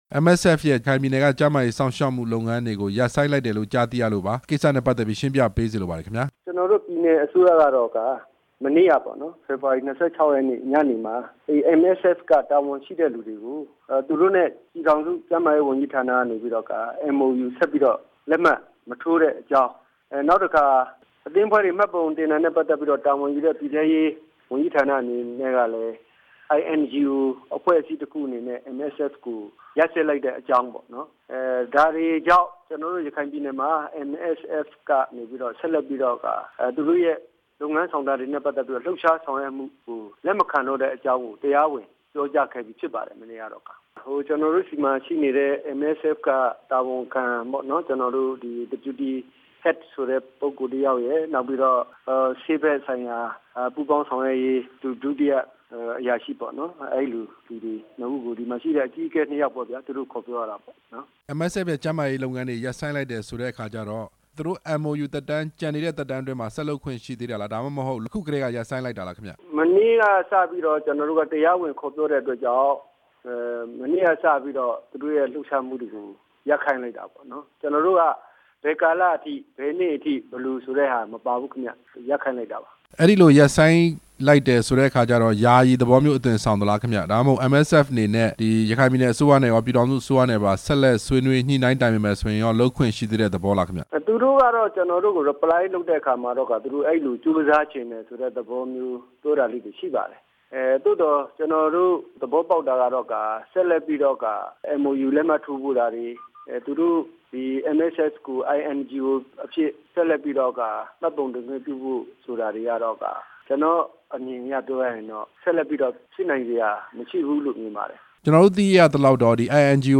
ပြည်နယ်ဥပဒေချုပ် ဦးလှသိန်းနဲ့ မေးမြန်းချက်